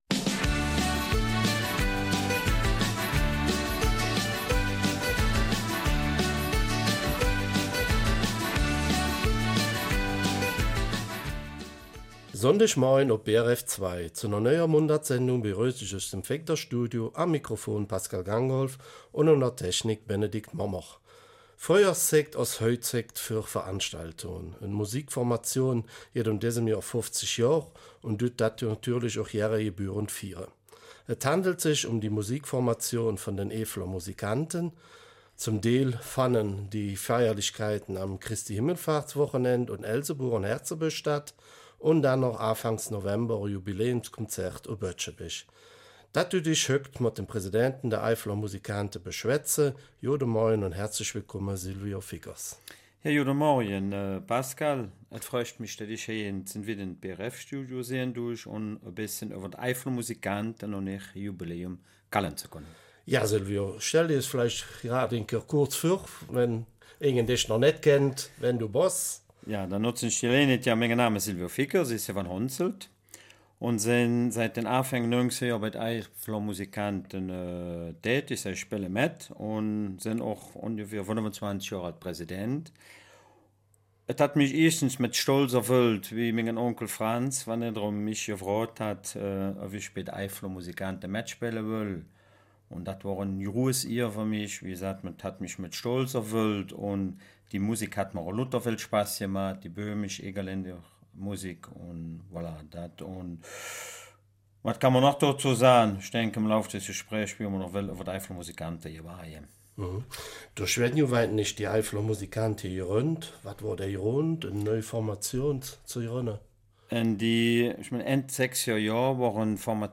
Eifeler Mundart: 50 Jahre Eifeler Musikanten - Ewig junge Blasmusik